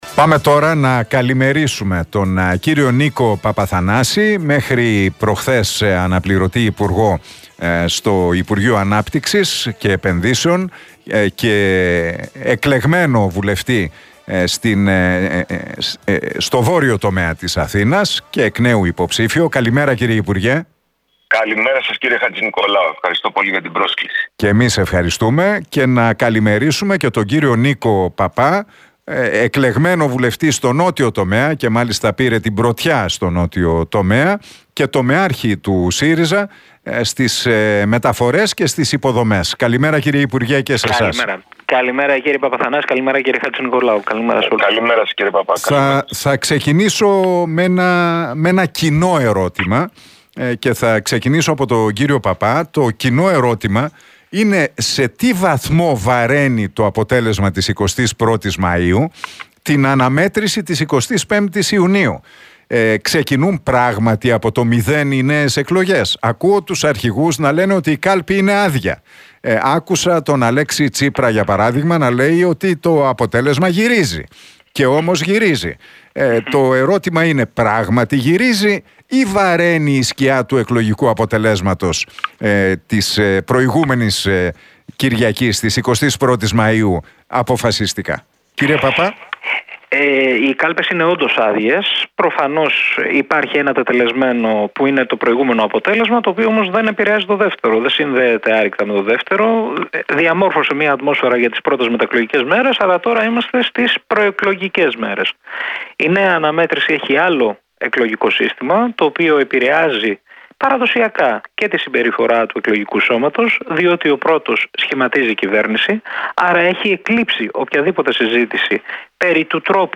Εκλογές 2023: Debate Παπαθανάση - Παππά στον Realfm 97,8
Τα ξίφη τους διασταύρωσαν στον αέρα του Realfm 97,8 και την εκπομπή του Νίκου Χατζηνικολάου ο Νίκος Παπαθανάσης από τη ΝΔ και ο Νίκος Παππάς από τον ΣΥΡΙΖΑ.